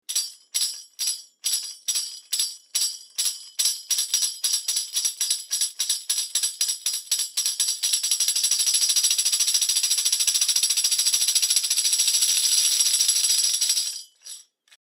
Звуки тамбурина